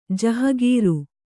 ♪ jahagīru